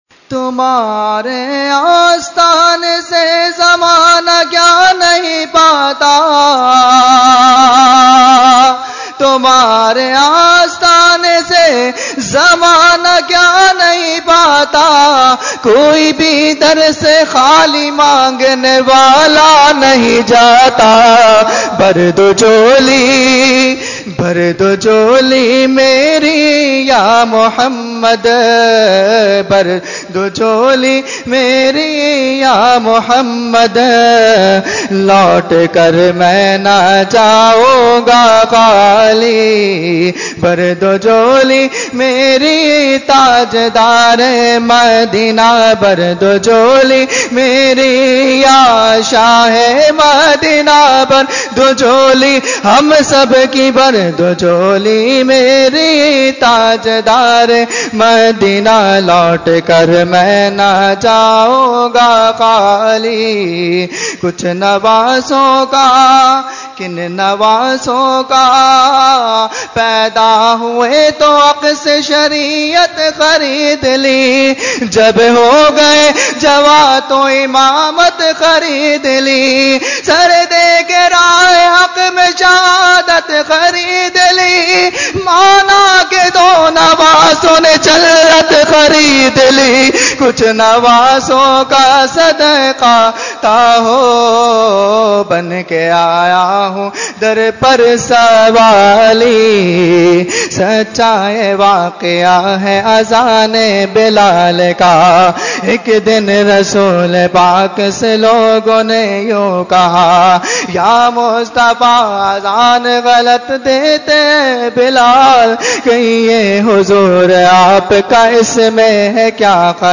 Category : Naat | Language : UrduEvent : Khatmul Quran 2016